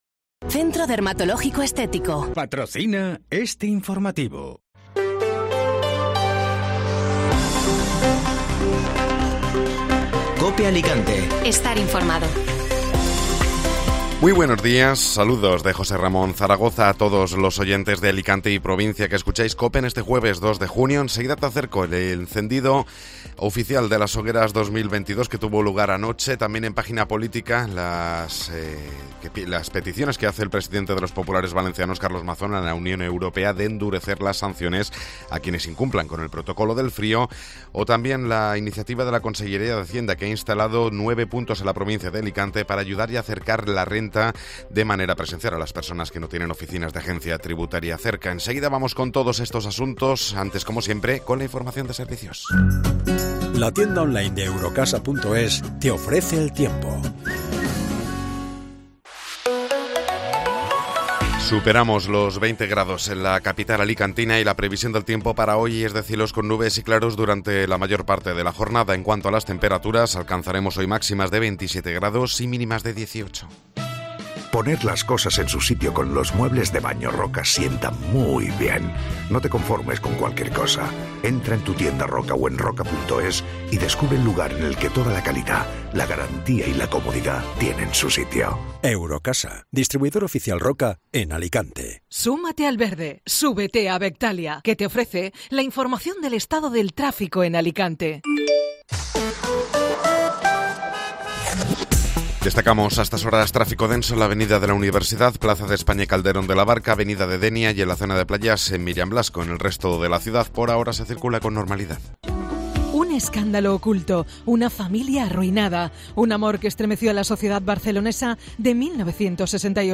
Informativo Matinal (Jueves 2 de Junio)